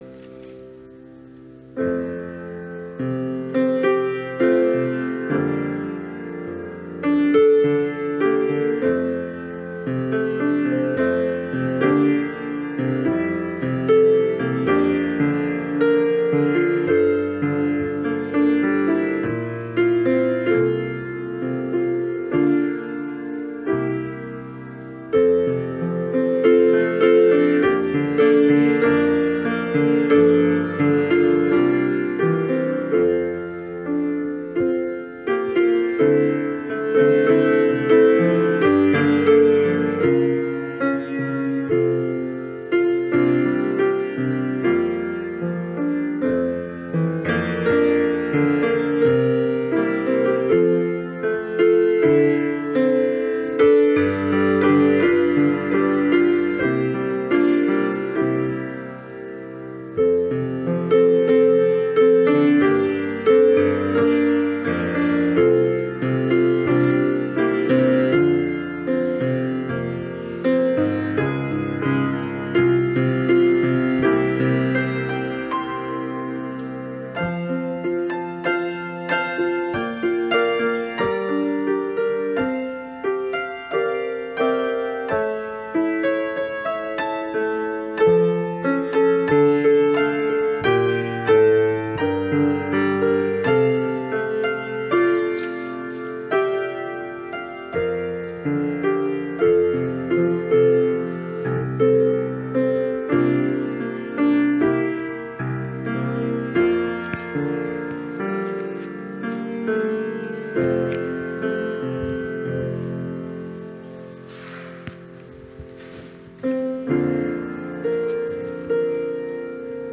Fellowship Church is pleased to offer this live service at 10AM.